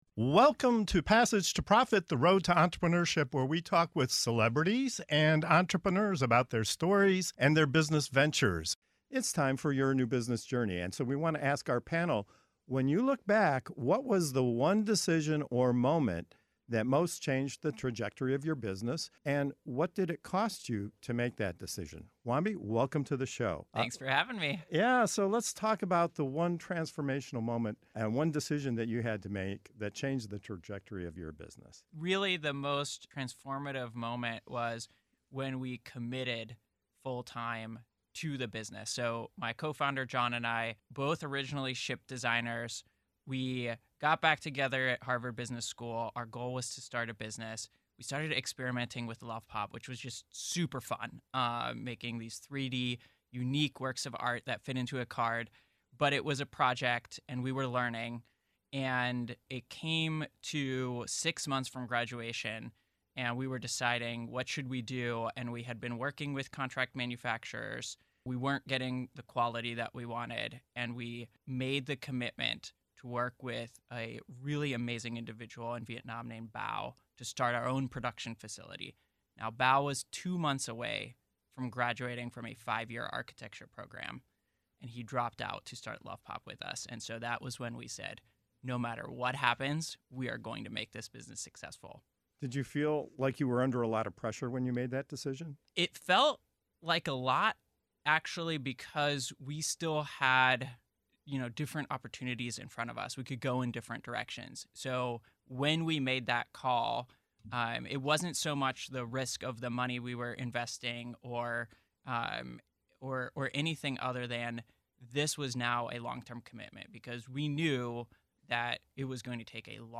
Whether you're a seasoned entrepreneur, a startup, an inventor, an innovator, a small business or just starting your entrepreneurial journey, tune into Passage to Profit Show for compelling discussions, real-life examples, and expert advice on entrepreneurship, intellectual property, trademarks and more.